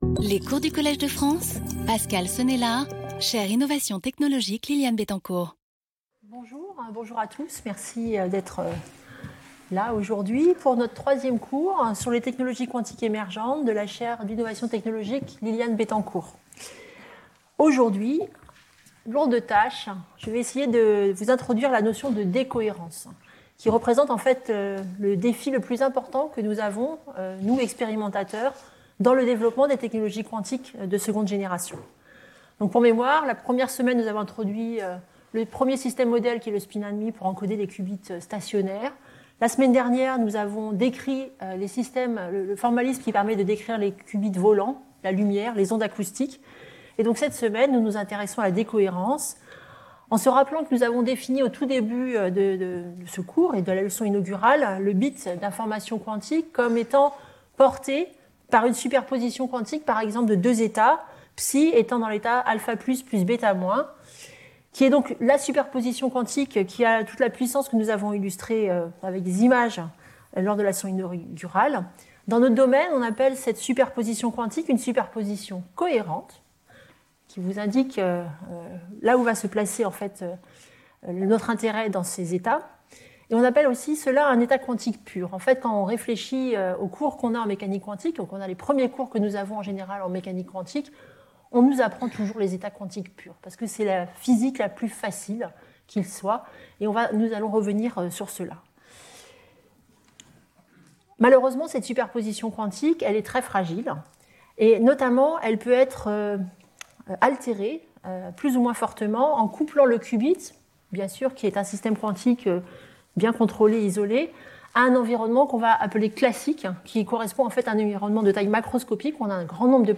In this lecture, we will introduce the notion of decoherence using simple physical models, before showing how these processes can be measured and what techniques can be used to mitigate them. We will then be able to present the DiVincenzo criteria, necessary for the realization of a quantum computer, and give a brief overview of the different experimental platforms and the main sources of decoherence that affect them.